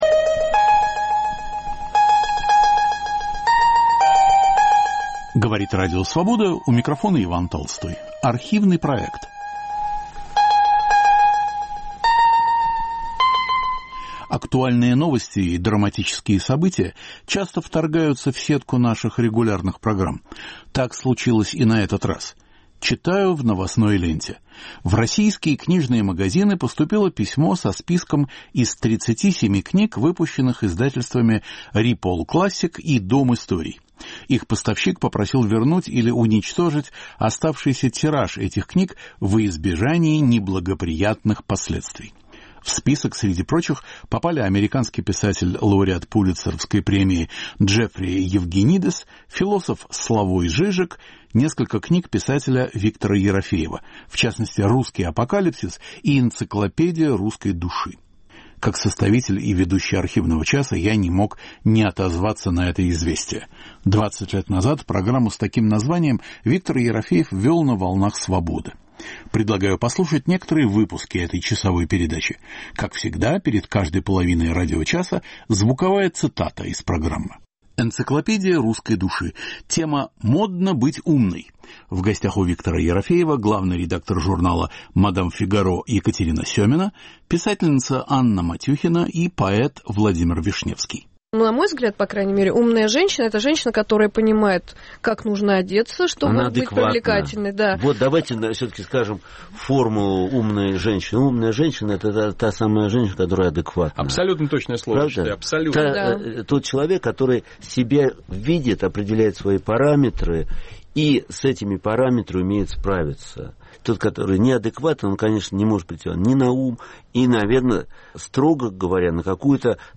Умная женщина - это женщина адекватная. В студии Радио Свобода
Автор и ведущий Виктор Ерофеев.